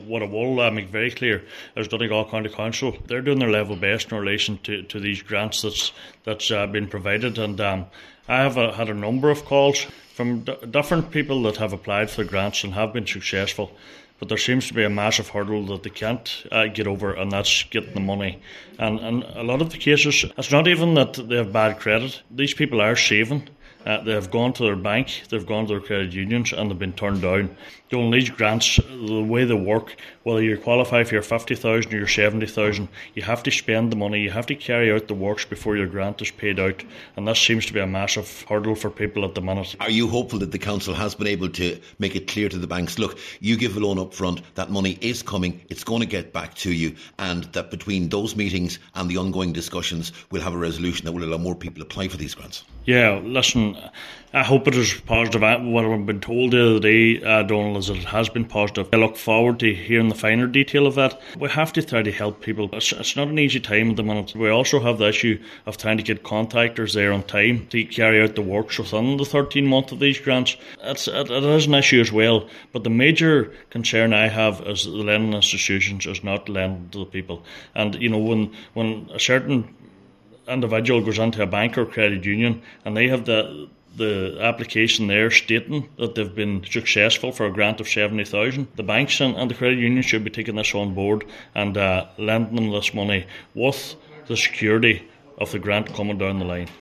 Cllr Kelly says it’s an important issue……..